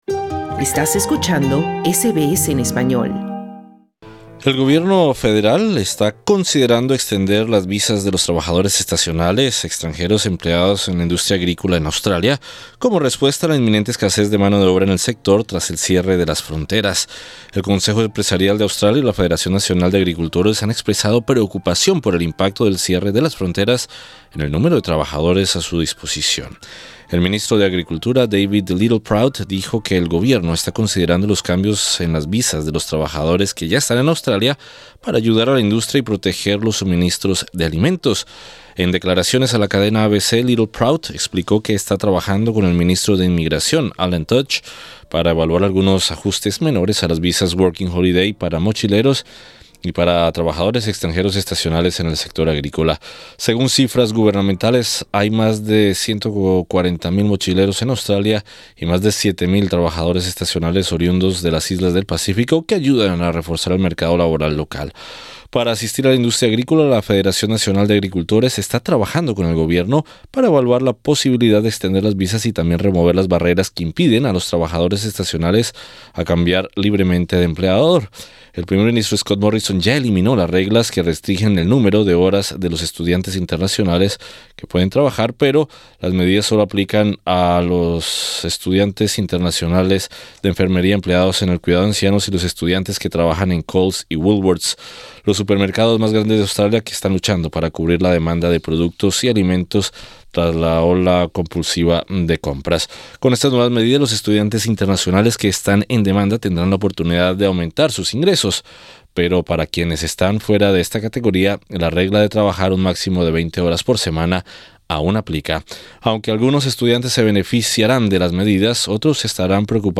Para contrarrestar la inminente escasez de mano de obra tras la decisión de cerrar las fronteras australianas para contener la propagación del COVID-19, el Gobierno está evaluando cambios para las visas Working Holdiay, que afectarían a los mochileros y trabajadores extranjeros estacionales en el sector agrícola. Escucha nuestro informe.